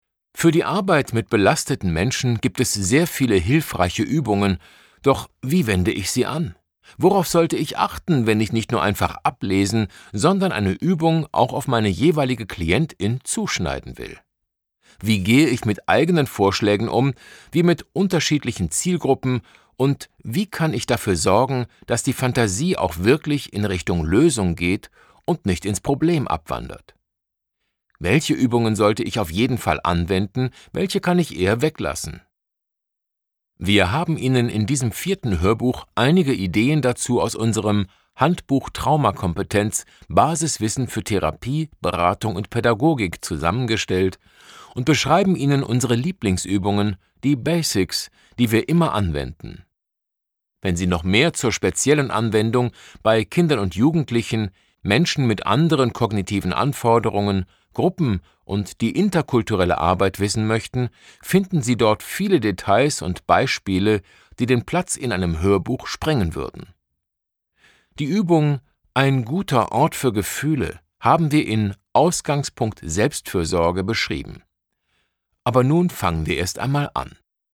Bemerkung: Dieses Hörbuch ist Teil 4 eines fünfteiligen Hörbuchs zum Thema „Traumakompetenz und Selbstfürsorge“.